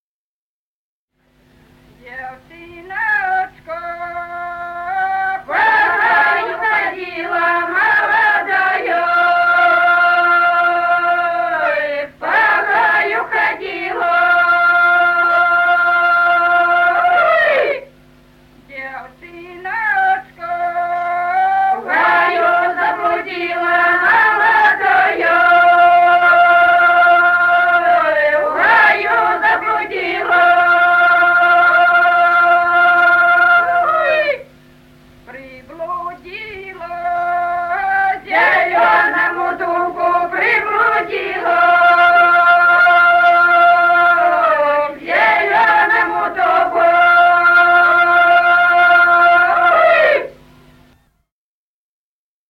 Народные песни Стародубского района «Девчиночка по гаю ходила», петровская.
с. Курковичи.